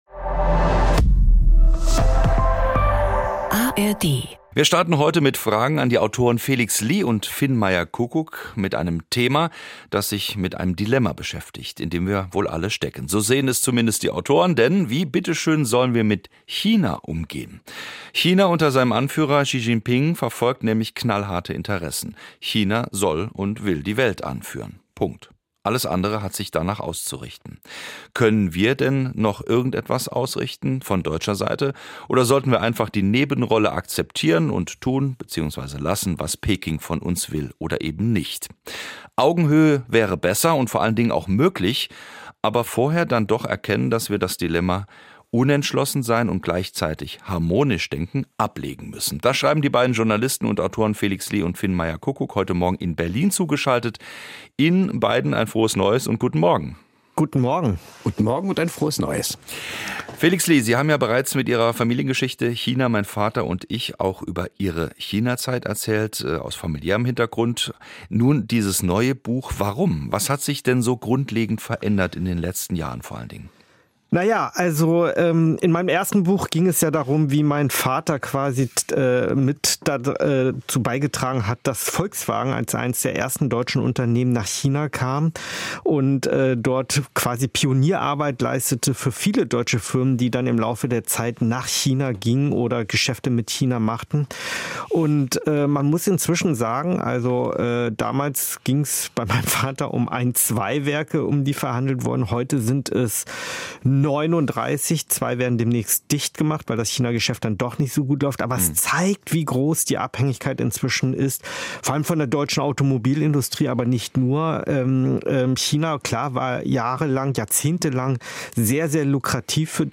Die traditionsreichste Sachbuchsendung im deutschen Sprachraum stellt seit über 50 Jahren jeweils ein Buch eines Autors eine Stunde lang im Gespräch vor. Die Themen reichen von Politik und Wirtschaft bis zu Gesundheit, Erziehung oder Psychologie.